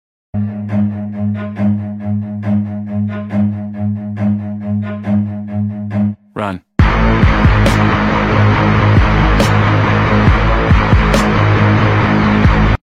効果音 走る
run.mp3